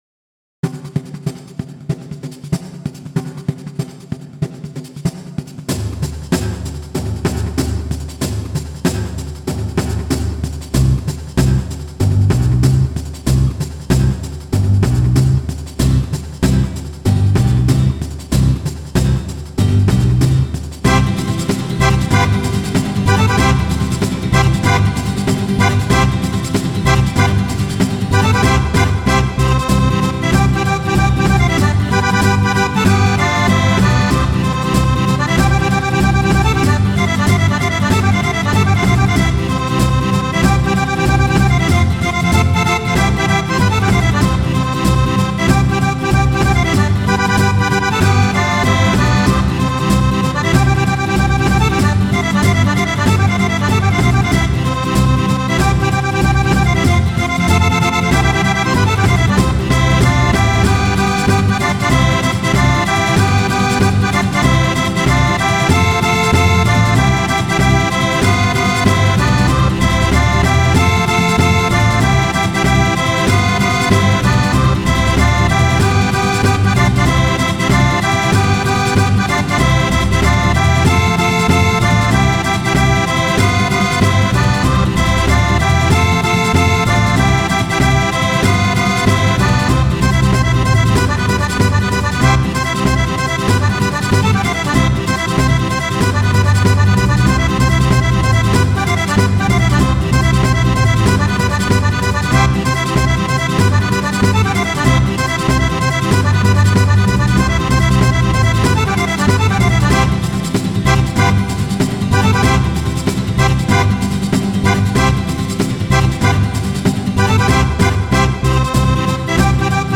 strumentale Pizzica salentina